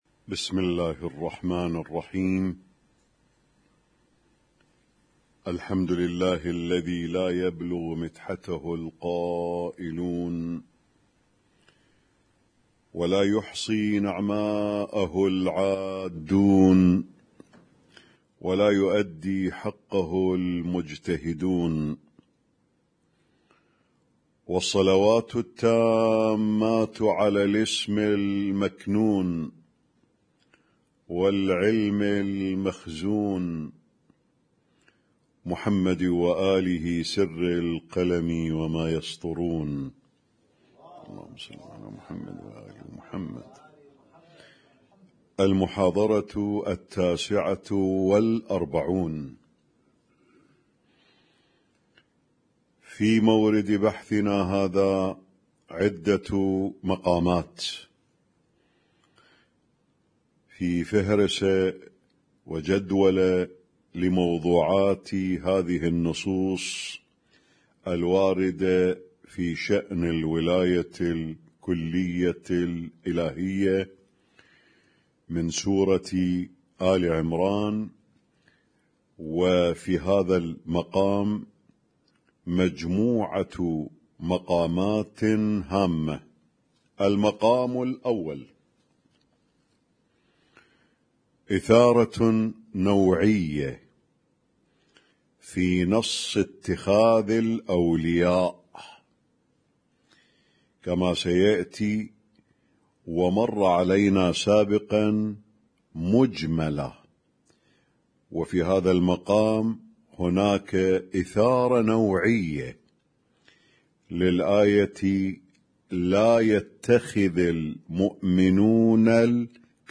قائمة المـكتبة الصــوتيه الدرس التاسع والأربعون
اسم التصنيف: المـكتبة الصــوتيه >> الدروس الصوتية >> الرؤية المعرفية الهادفة